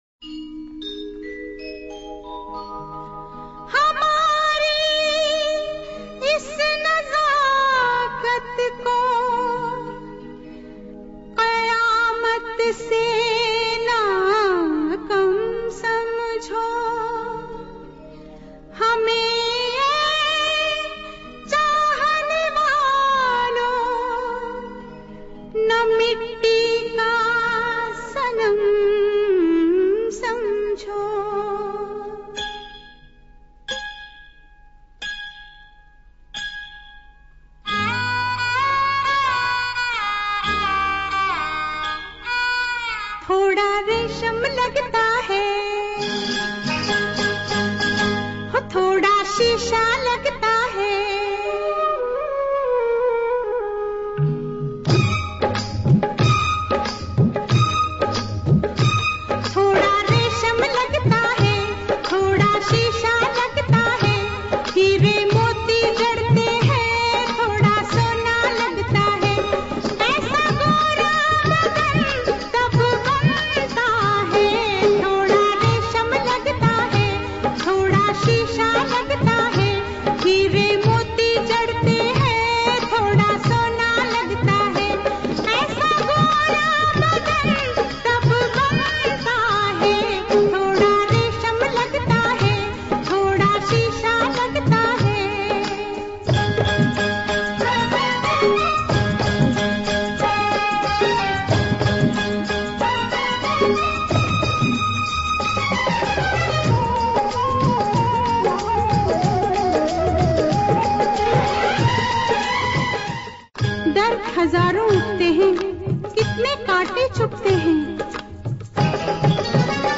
Killa beats !